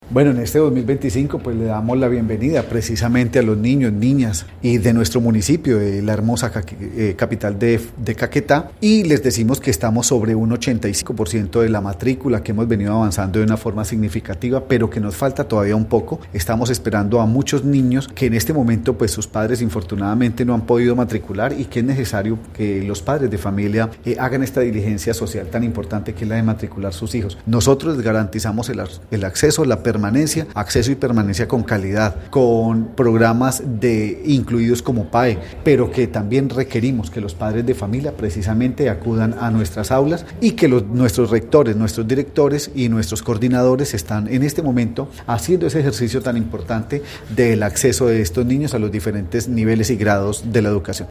Fenner de los Ríos Barrera, secretario de educación municipal, explicó que en la actualidad hay cupos en varias sedes educativas como el Juan Bautista Migani y el Jorge Eliecer Gaitán.